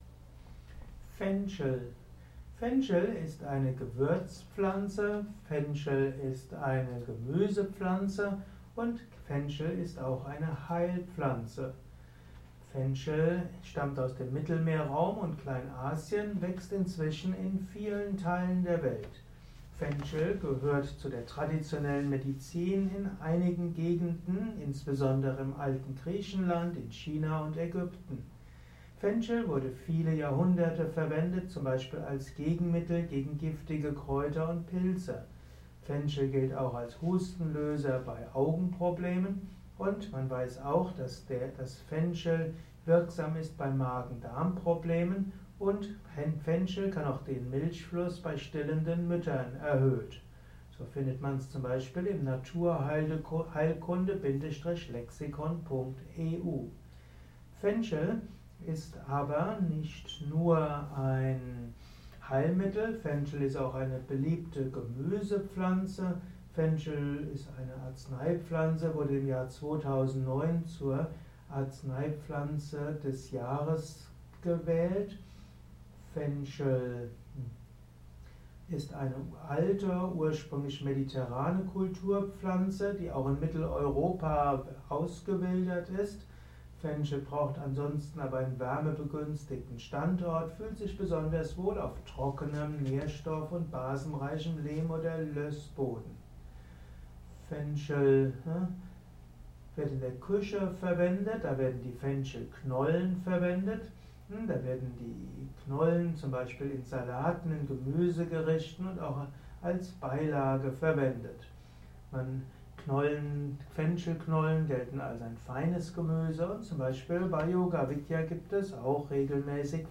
Ein Kurzvortrag über Fenchel